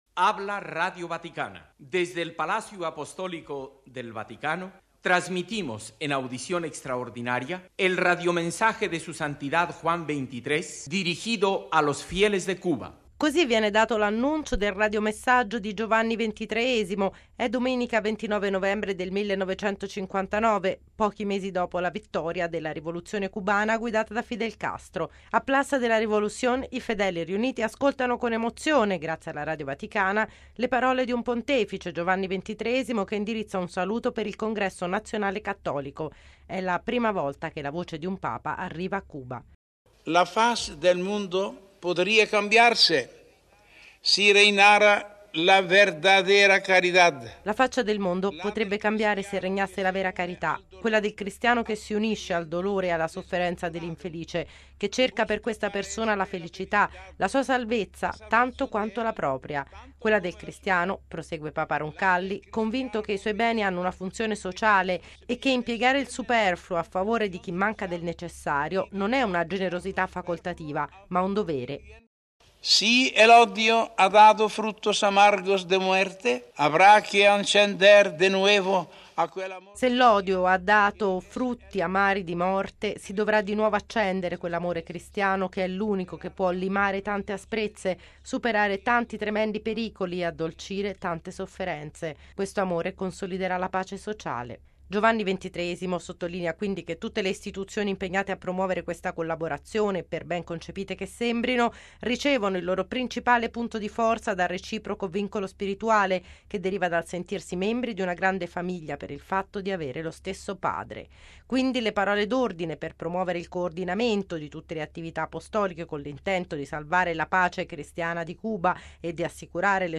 Così viene dato l’annuncio del Radiomessaggio di Giovanni XXIII.